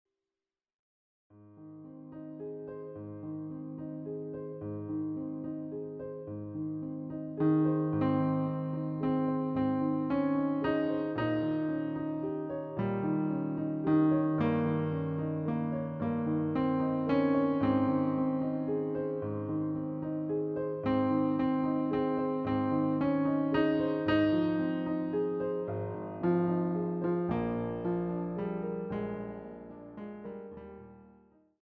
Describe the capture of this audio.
CD quality digital audio using the stereo sampled sound of a Yamaha Grand Piano.